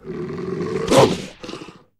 Cri de Grondogue dans Pokémon HOME.